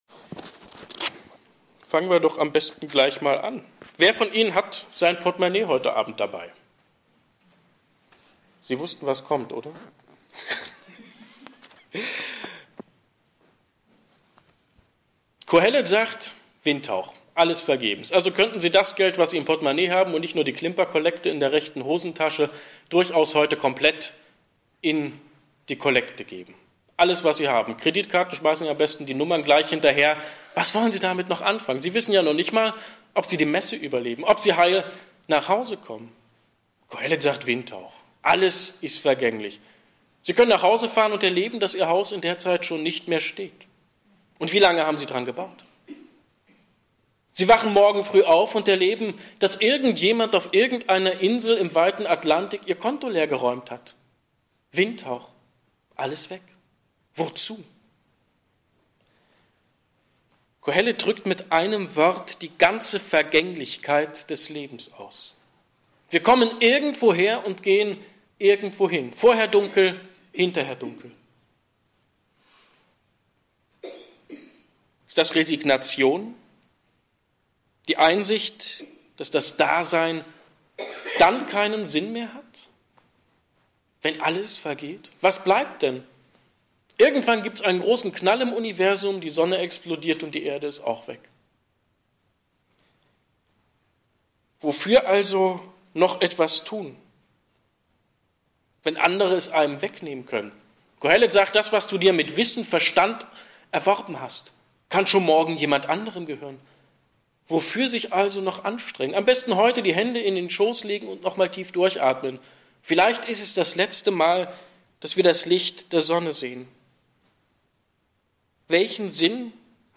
18. Sonntag im Jahreskreis, Lesejahr C
hier-klickt-die-predigt.mp3